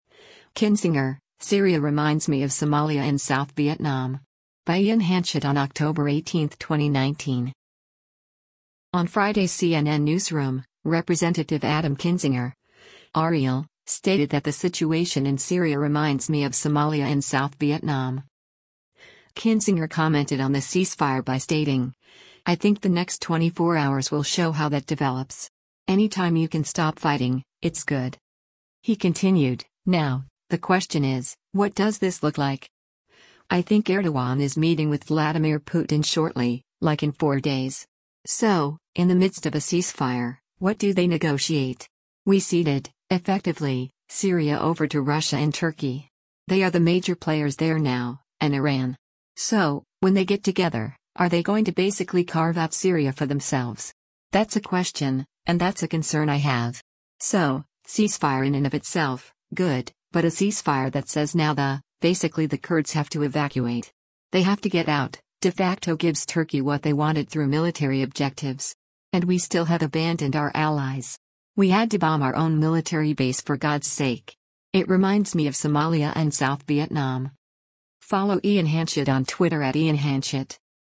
On Friday’s “CNN Newsroom,” Representative Adam Kinzinger (R-IL) stated that the situation in Syria “reminds me of Somalia and South Vietnam.”